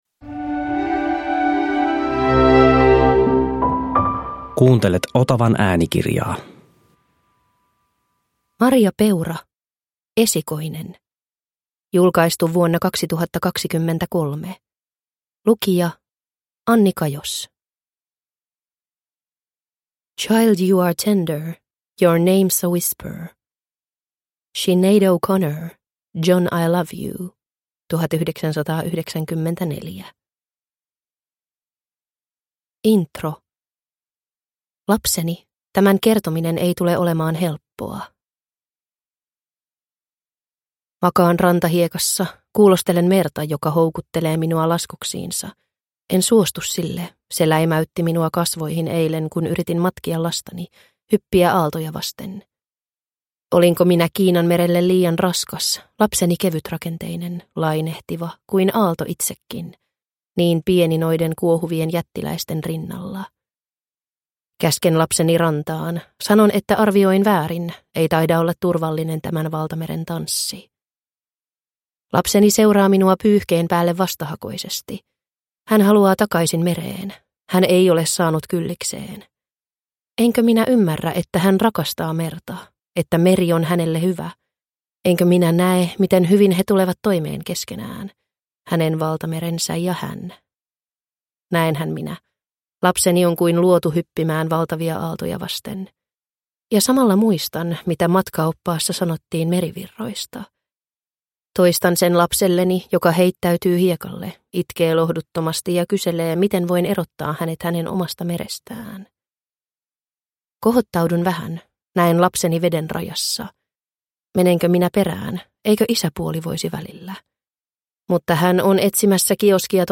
Esikoinen – Ljudbok – Laddas ner